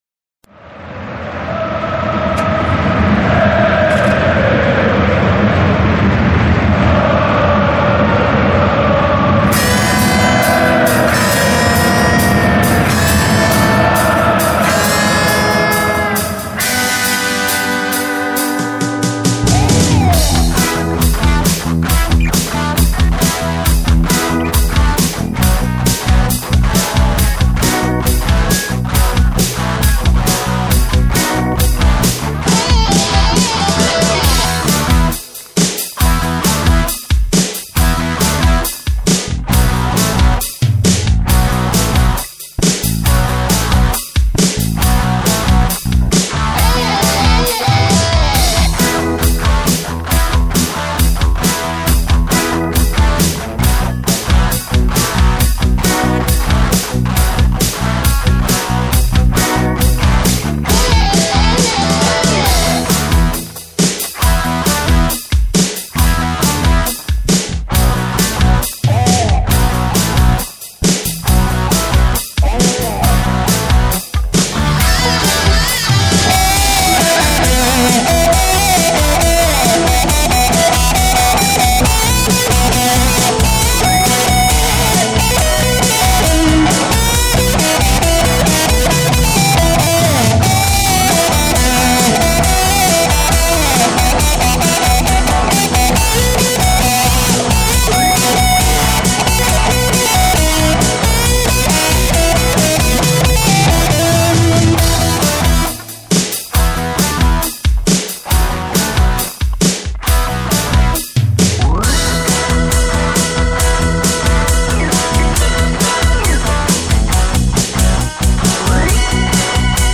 Playback (MP3)